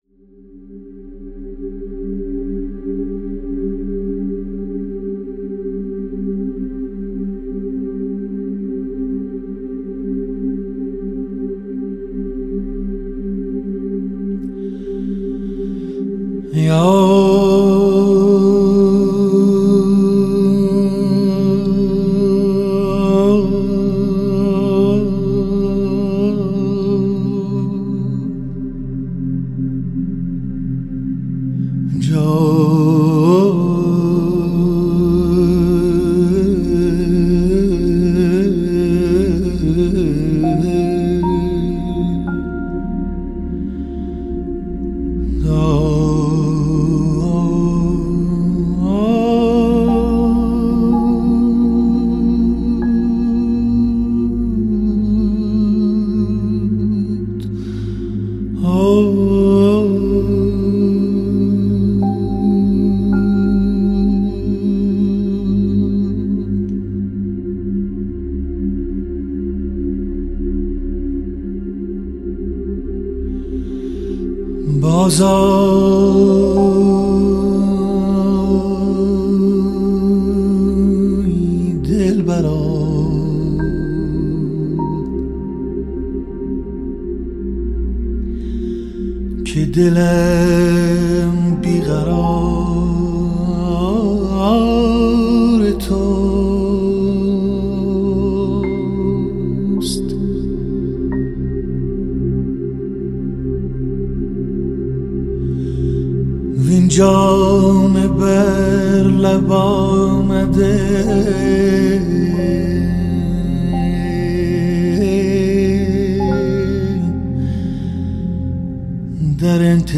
خواننده برجسته موسیقی سنتی